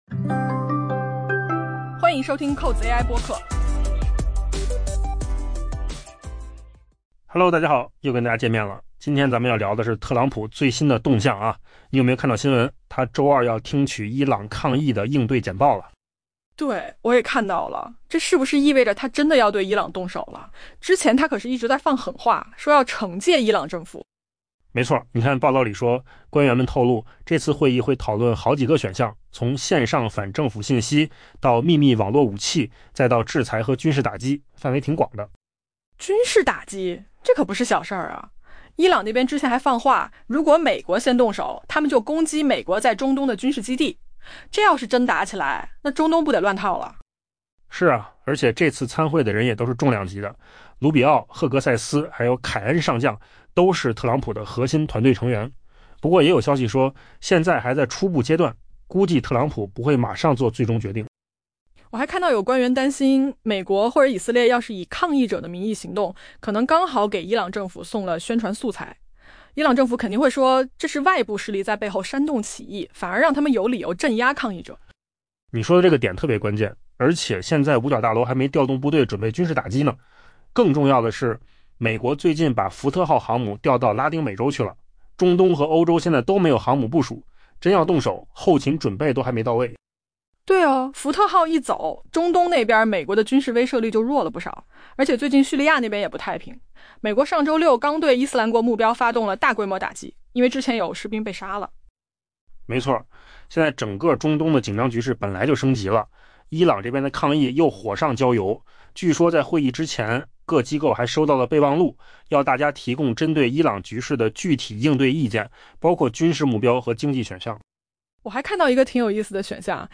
AI 播客：换个方式听新闻 下载 mp3 音频由扣子空间生成 据 《华尔街日报》 报道，美国官员称， 特朗普定于周二听取有关应对伊朗抗议活动选项的简报，这表明他正考虑兑现其屡次威胁——就当局打压示威者的行为惩戒该政权。